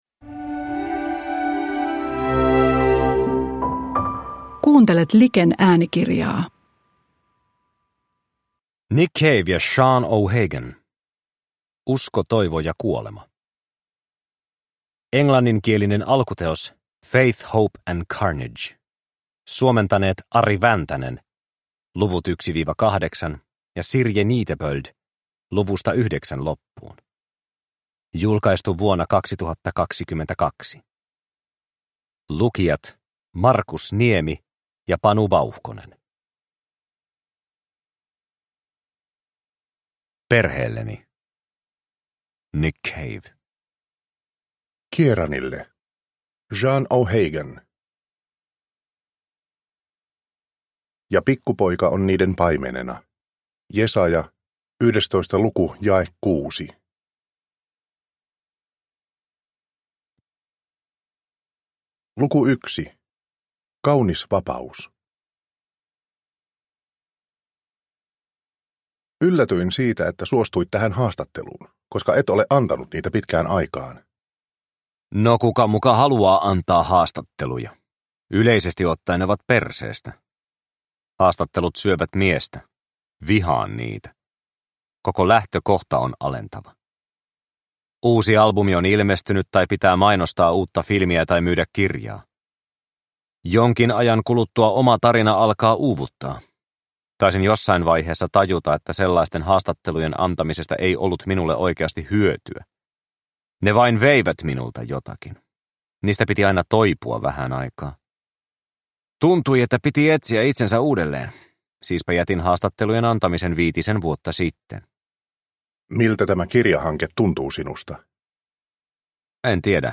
Usko, toivo ja kuolema – Ljudbok